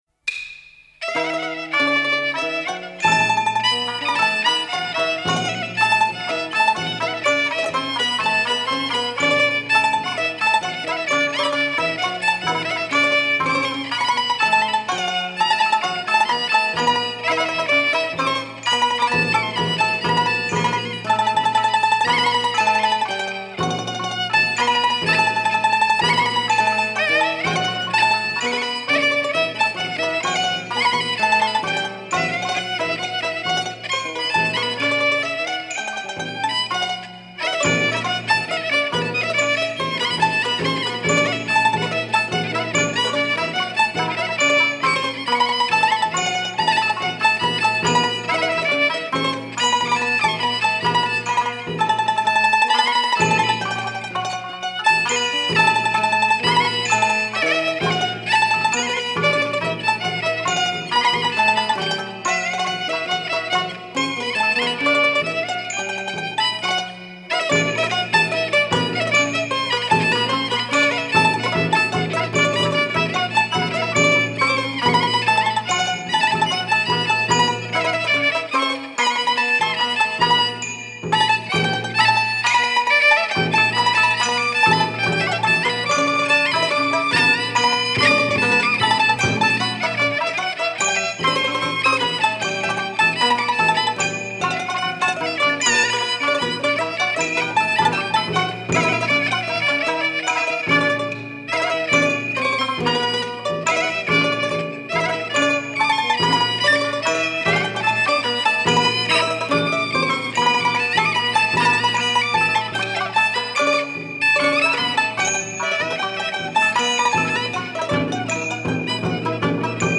0023-京胡名曲哭天皇.mp3